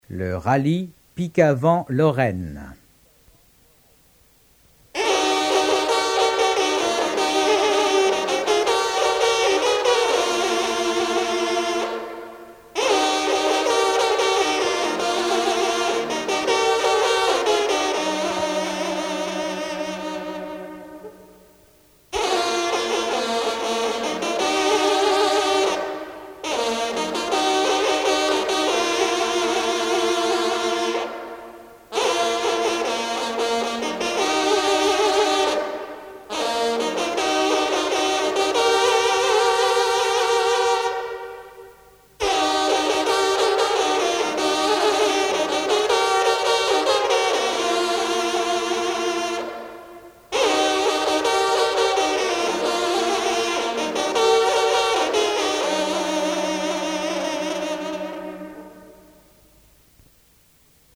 sonnerie vénerie - fanfare d'équipage
circonstance : vénerie